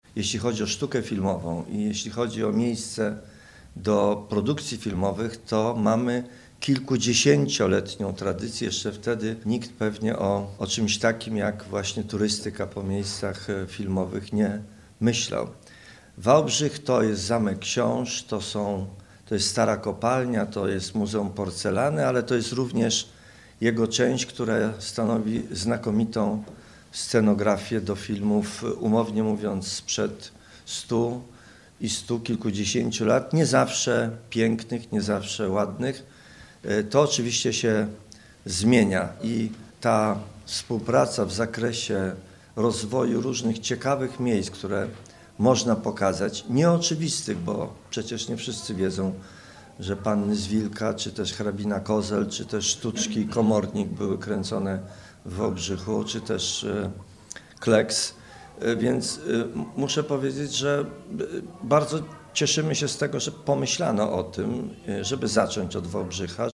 Prezydent Wałbrzycha Roman Szełemej zaznaczył, że miasto ma bogate tradycje filmowe i wielokrotnie stanowiło naturalne plany zdjęciowe.